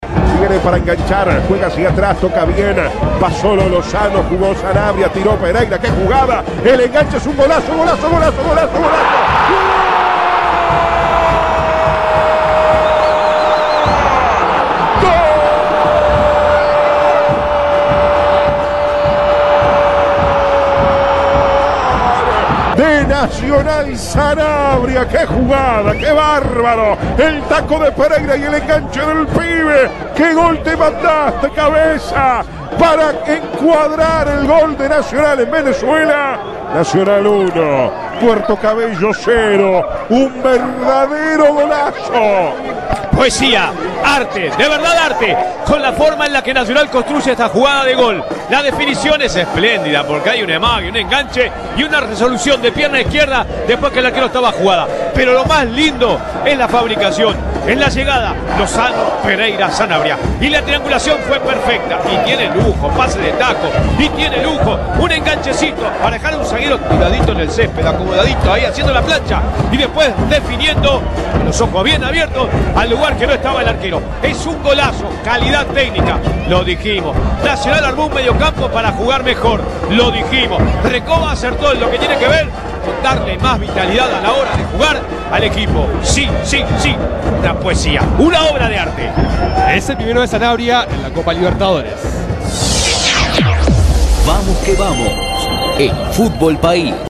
El debut tricolor en copa en la voz del equipo de Vamos que Vamos